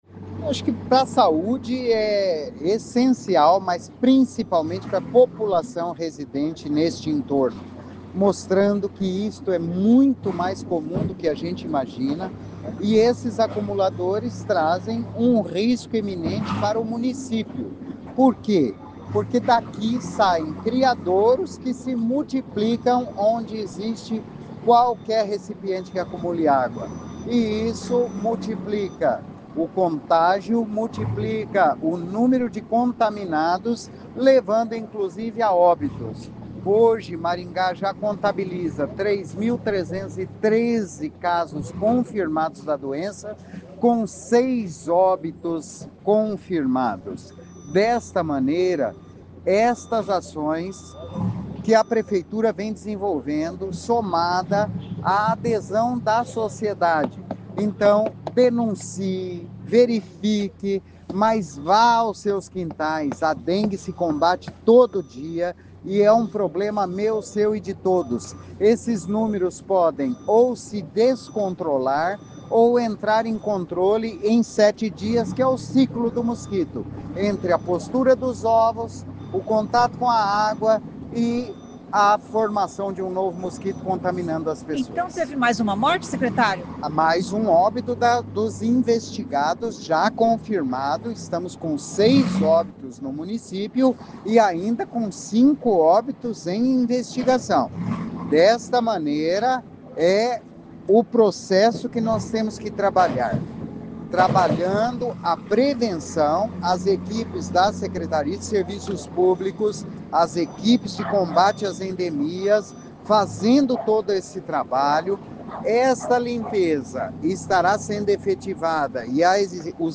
O secretário de Saúde Antonio Carlos Nardi  disse que o acumulador é reincidente.